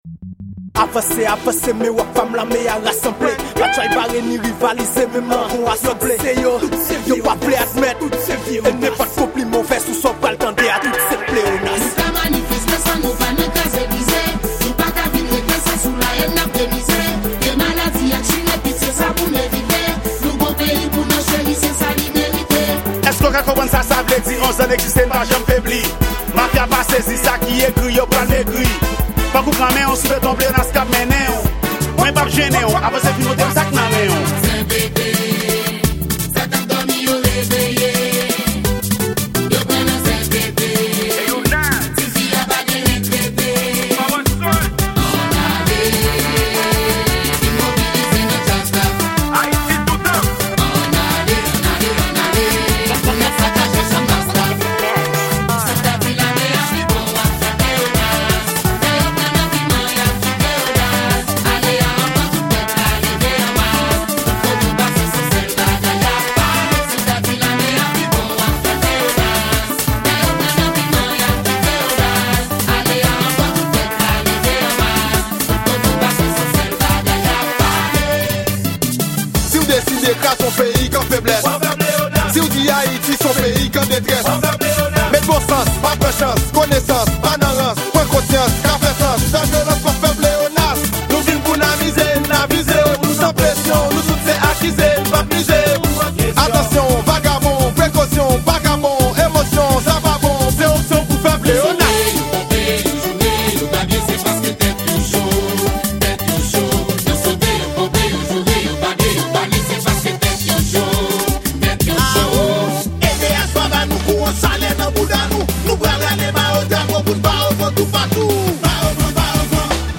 Genre: KANVAL.